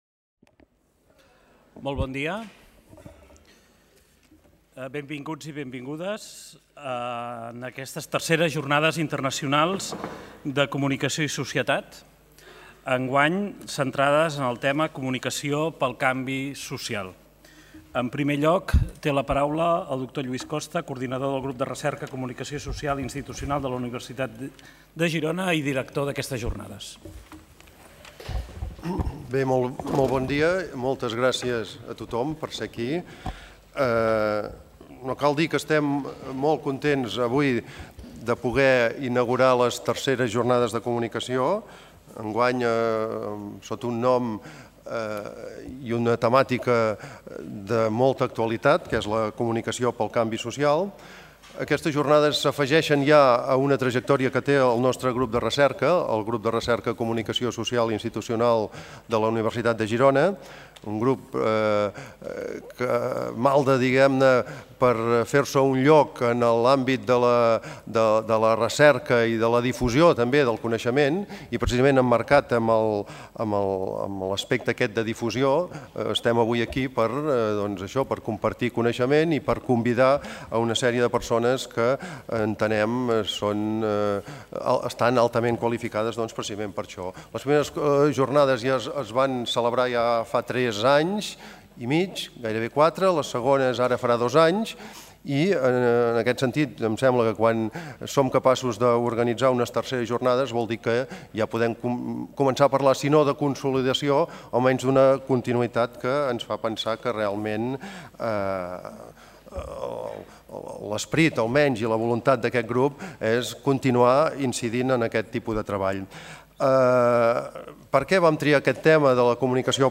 Obertura de les III Jornades Internacionals de Comunicació i Societat